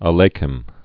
(ä-lākĕm, -ĕm), Sholem or Sholom Originally Solomon Rabinowitz. 1859-1916.